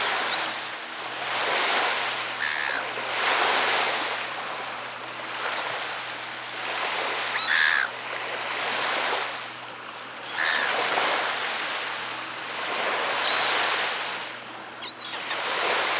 pgs/Assets/Audio/Animals_Nature_Ambiences/beach_waves_seaguls_01.WAV at master
beach_waves_seaguls_01.WAV